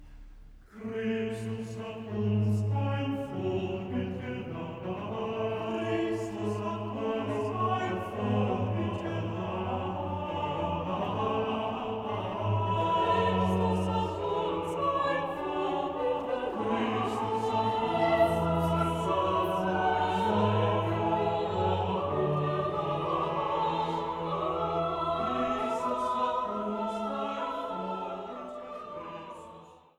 Passionskantate für Soli, Chor und Orchester
Aria (Bass) „So stehet ein Berg Gottes“